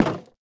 Minecraft Version Minecraft Version snapshot Latest Release | Latest Snapshot snapshot / assets / minecraft / sounds / block / nether_wood_fence / toggle2.ogg Compare With Compare With Latest Release | Latest Snapshot
toggle2.ogg